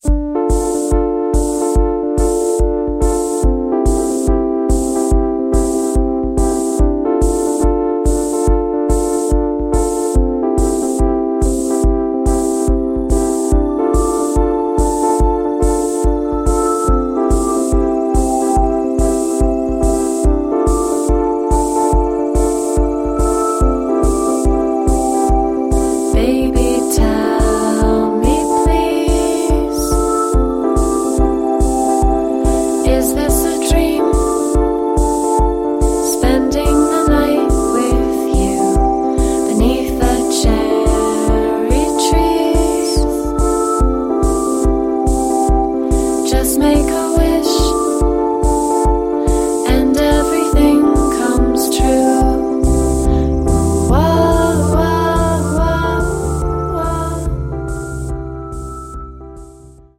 świeżym, marzycielskim tercecie synth-popowym